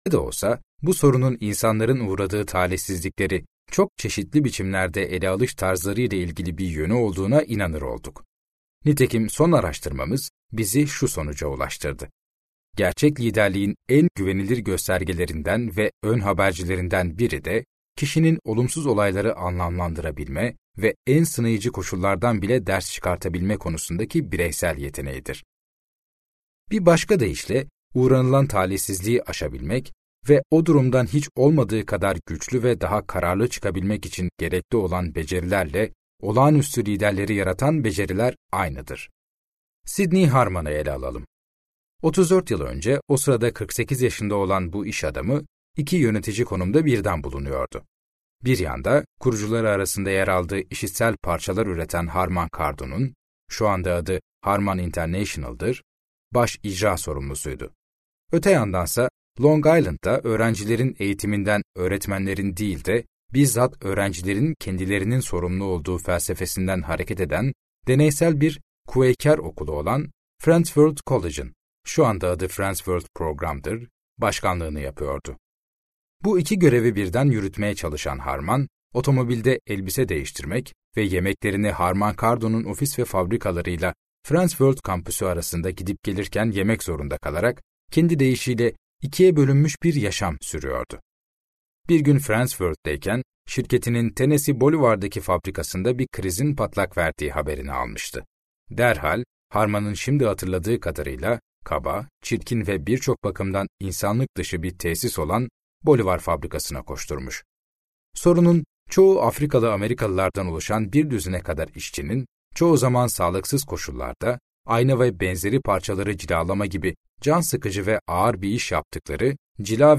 Liderlik Potaları - Seslenen Kitap